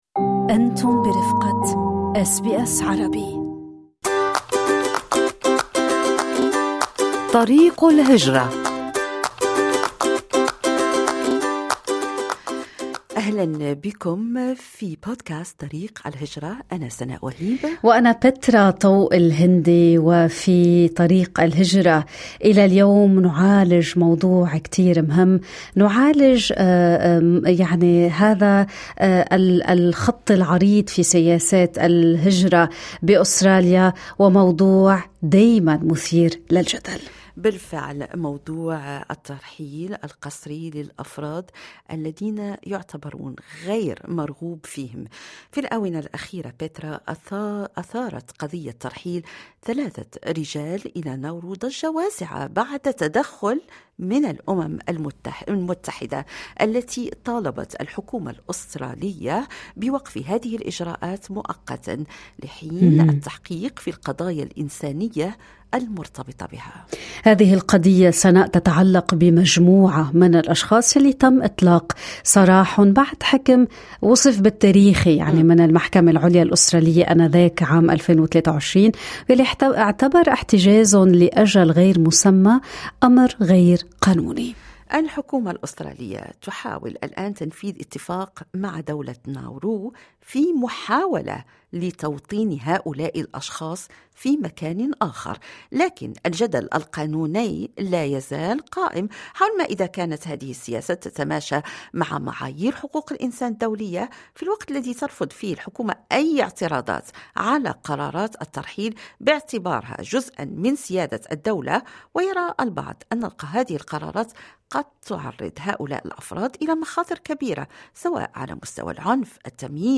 في الولايات المتّحدة، تتصدّرُ عناوينَ الأخبارِ قضيّةُ حقّ الإطّلاع على بياناتِ الطُلّاب في جامعتَي كولومبيا وبراون، وسطَ تفاعلاتٍ قانونيّة وسياسيّة مُستمرّة. تفاصيلُ هذه القضيّة وأخبارٌ أُخرى نعرِضُها لكم في هذا التقرير مع مُراسلِنا في أمريكا.
تقرير المراسل